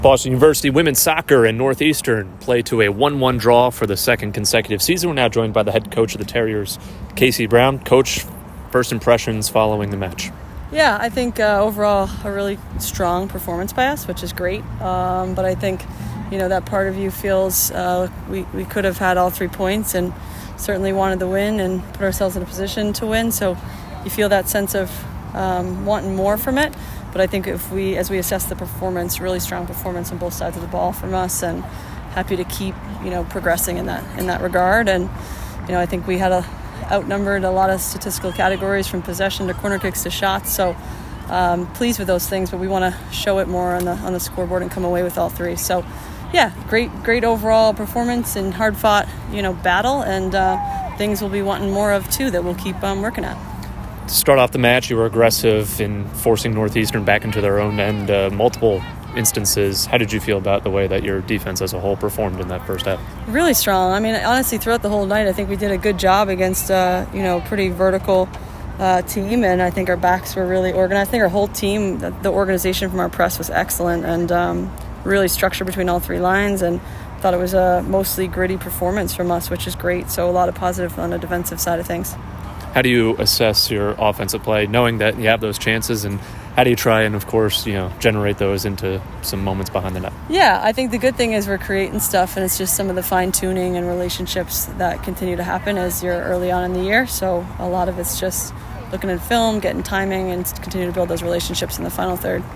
Northeastern Postgame Interview